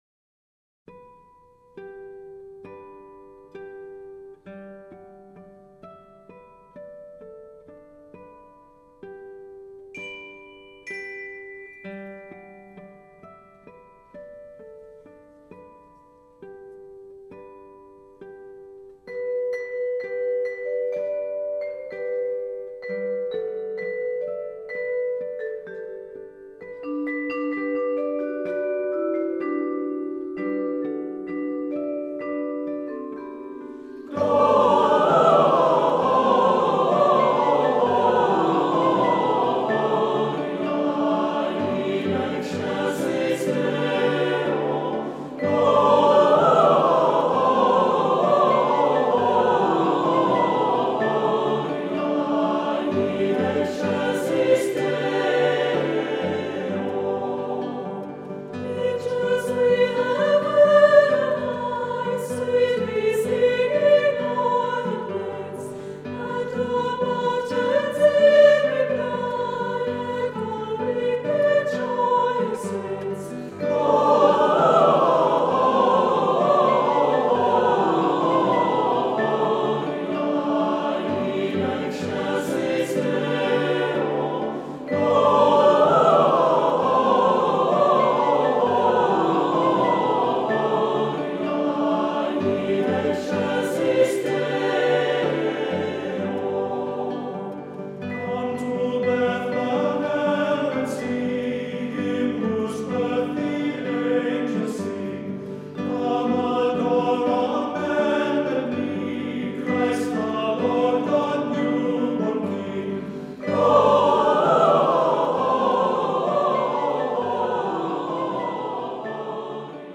Guitar
Native American Flute